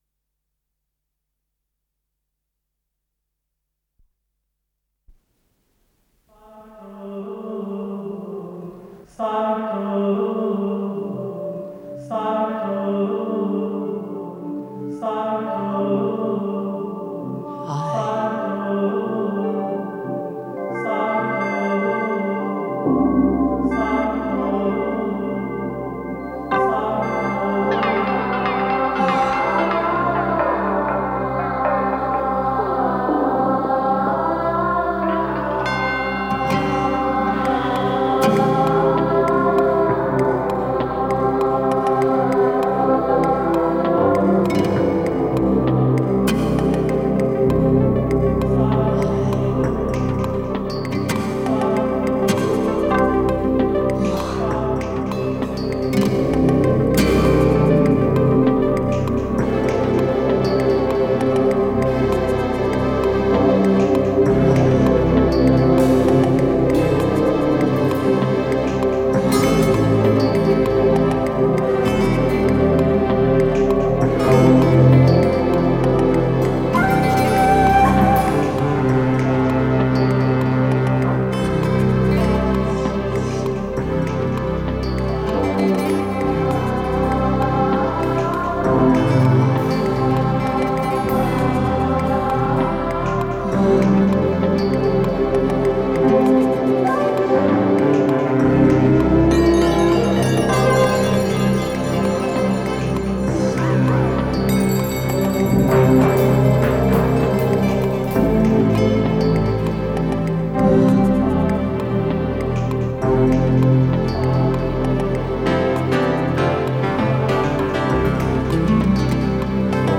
с профессиональной магнитной ленты
ПодзаголовокПьеса
Скорость ленты38 см/с